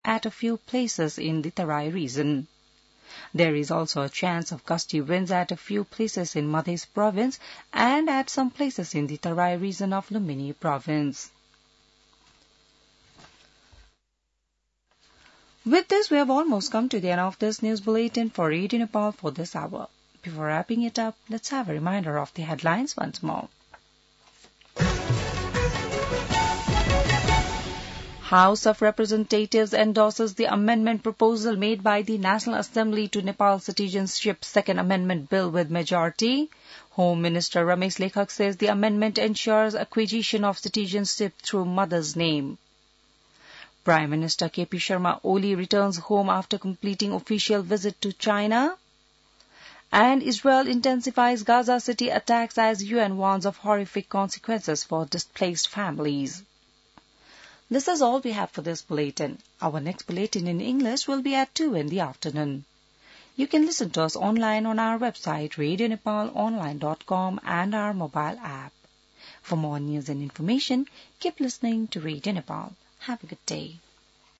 बिहान ८ बजेको अङ्ग्रेजी समाचार : १९ भदौ , २०८२